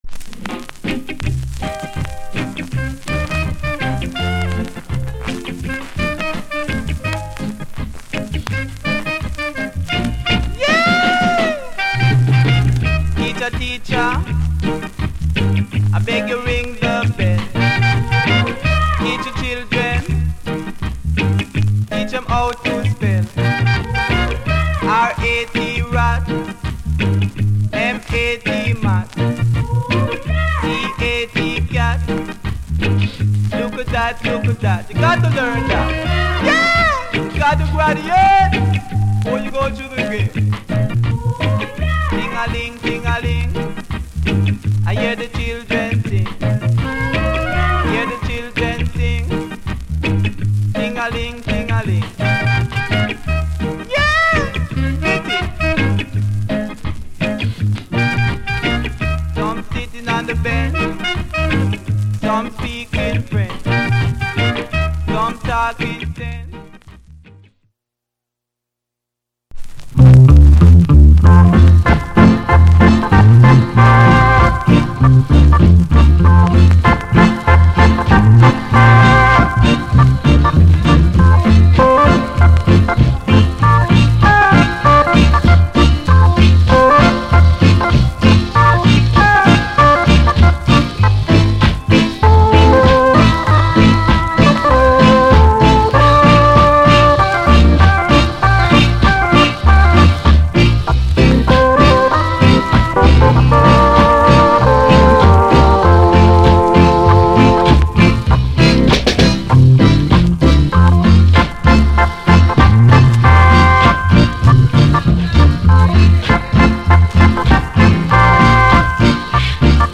Genre Early Reggae / Male DJ